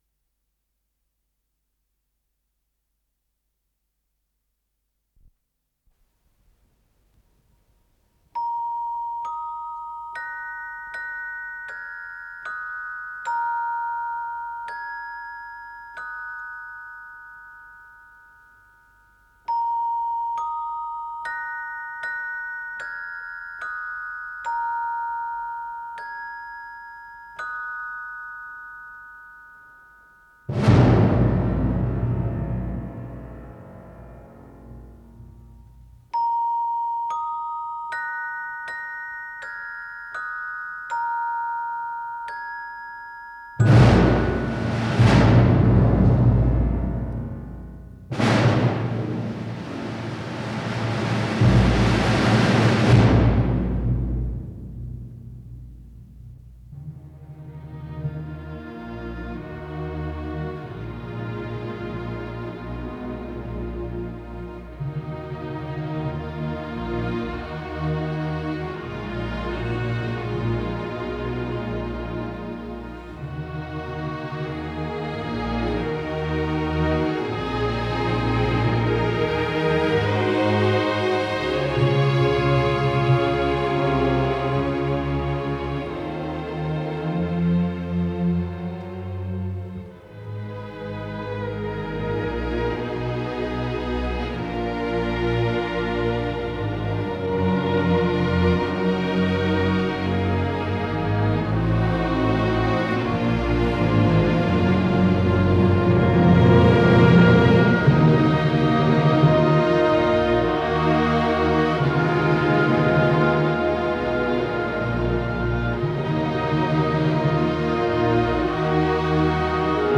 Скорость ленты38 см/с
Тип лентыШХЗ Тип 2